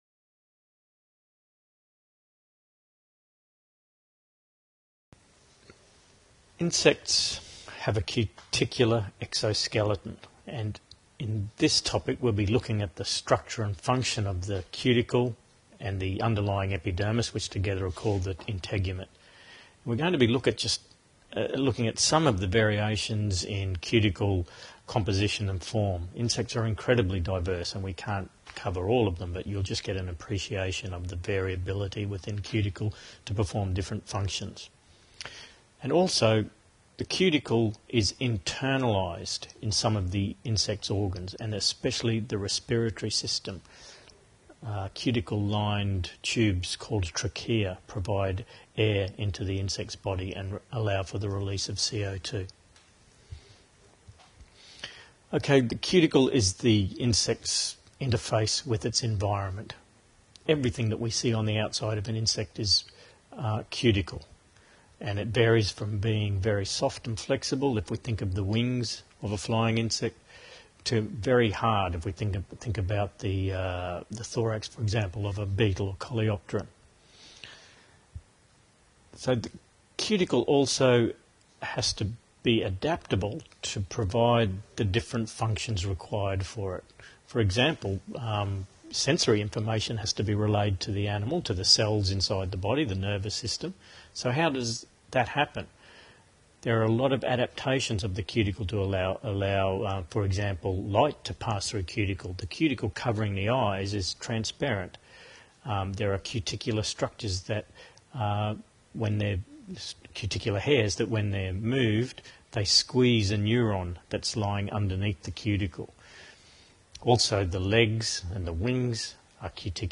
Mini-lecture: